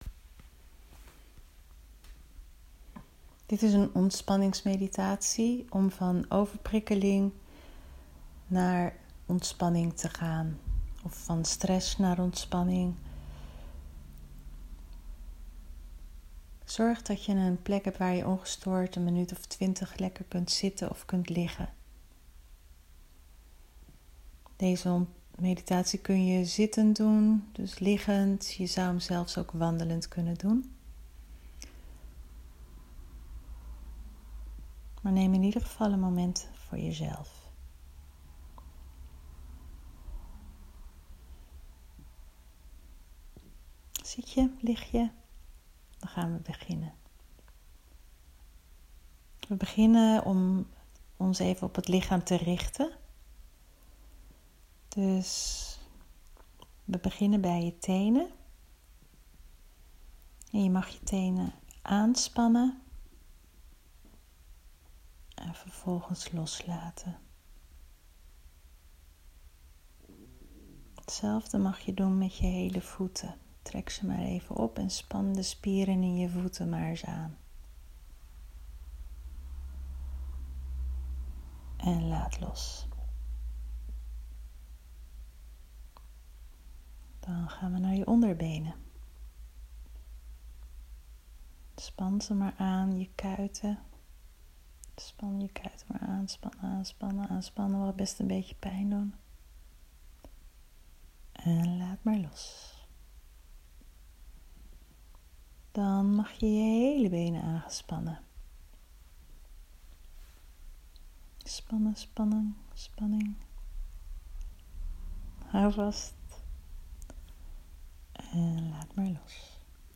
Een rustgevende – of ontspanningsmeditatie kan je helpen om je systeem te kalmeren, om je gedachtestroom te stoppen.
Meditatie-van-overprikkeling-naar-rust-en-veiligheid.m4a